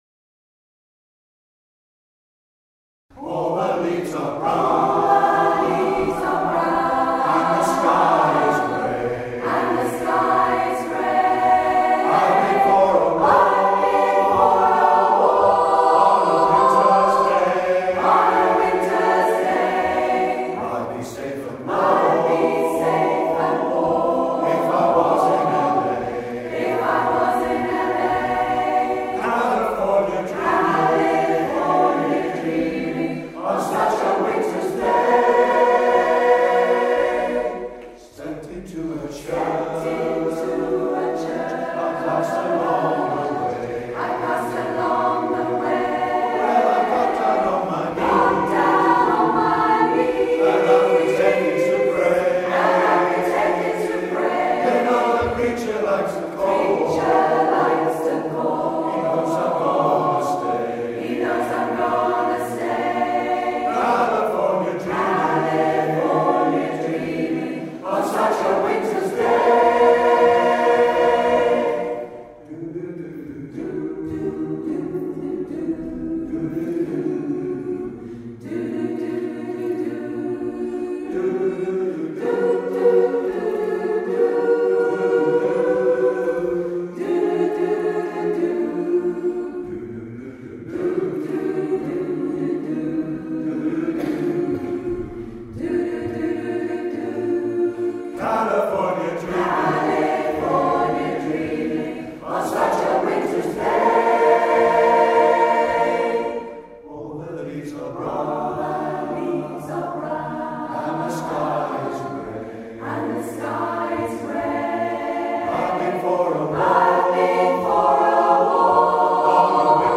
Zvuková ukázka z vystoupení v kostele Panny Marie v Železné Rudě